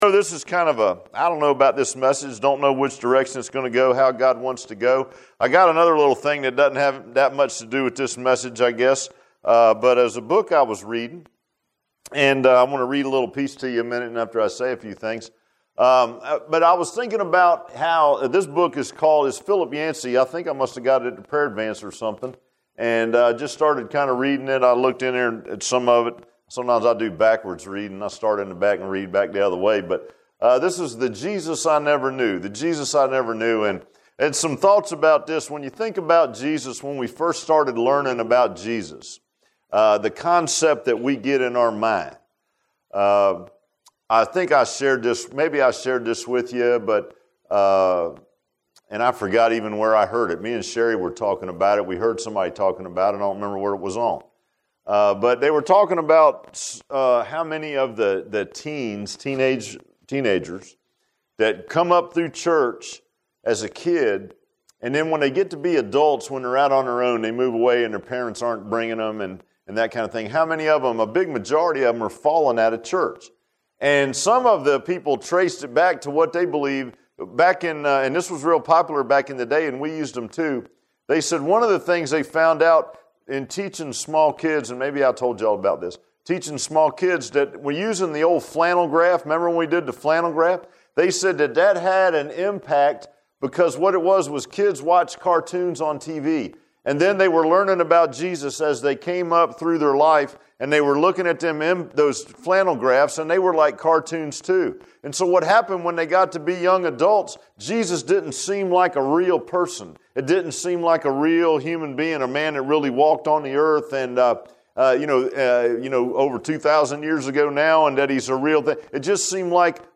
Matthew 13 Service Type: Sunday PM Bible Text